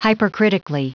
Prononciation du mot hypercritically en anglais (fichier audio)
Prononciation du mot : hypercritically